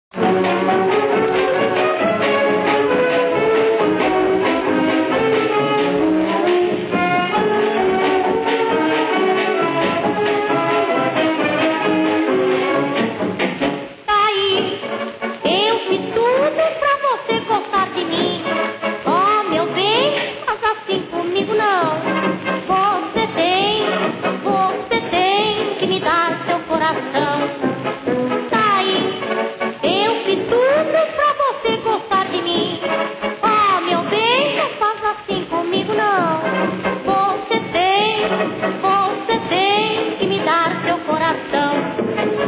marcha carnavalesca